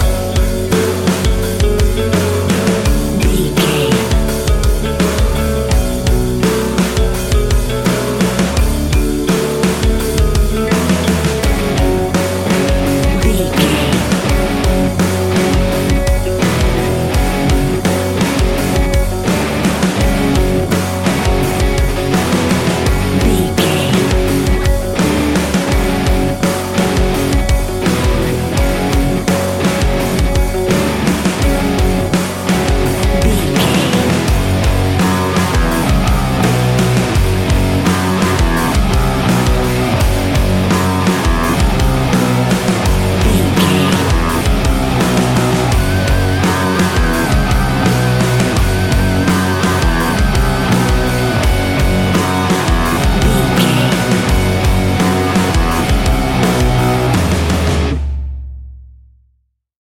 Ionian/Major
F♯
heavy metal